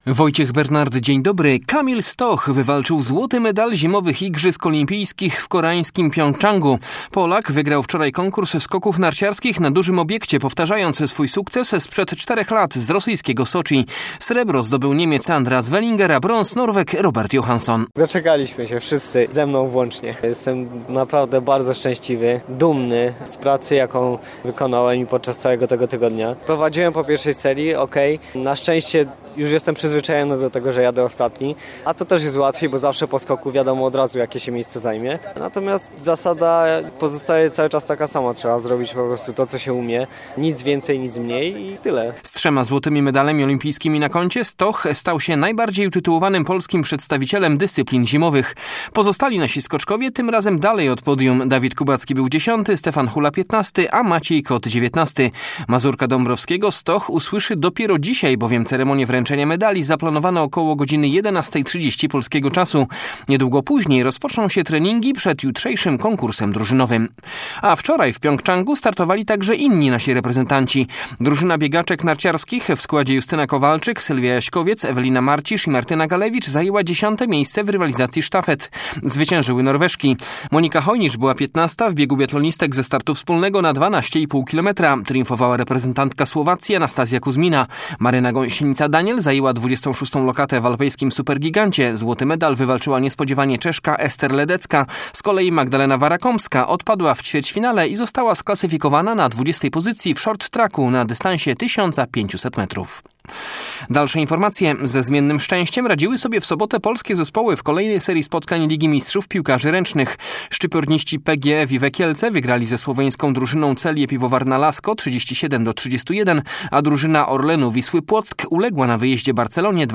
18.02 serwis sportowy godz. 9:05